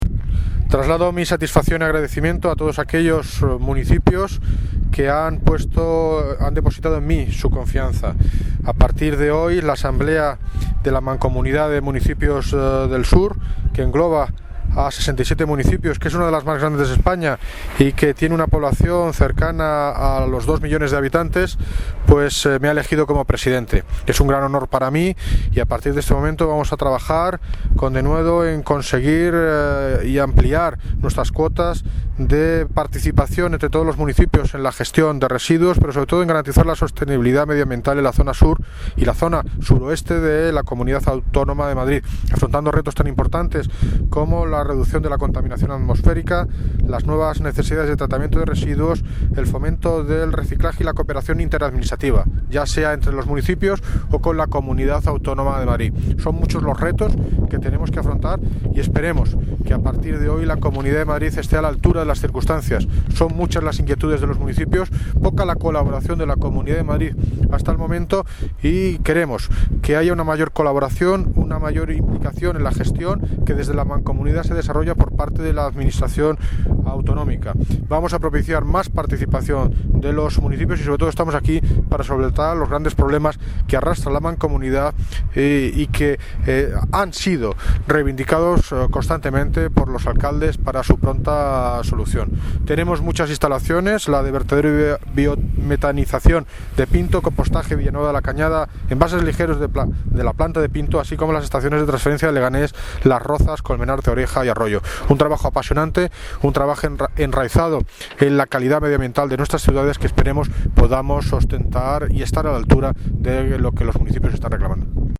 Audio - David Lucas (Alcalde de Móstoles ) Sobre Elección Presidente de la Mancomunidad del Sur